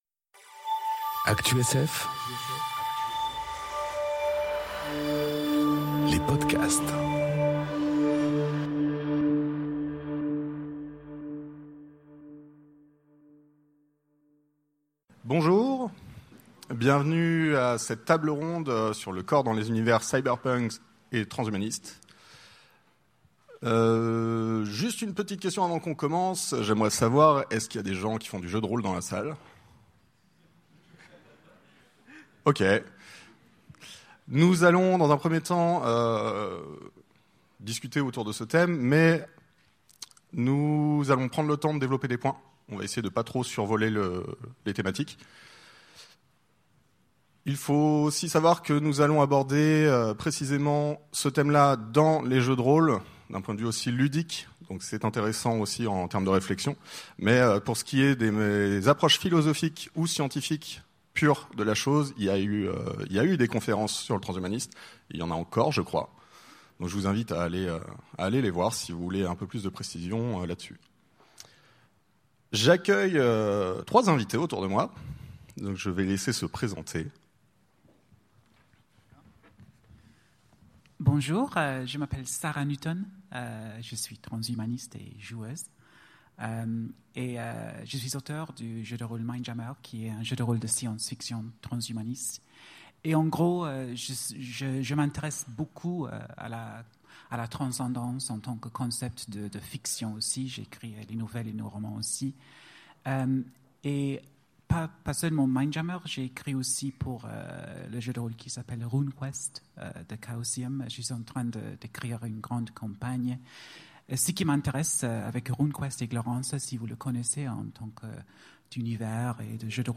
Conférence Le corps dans les univers cyberpunk et transhumanistes enregistrée aux Utopiales 2018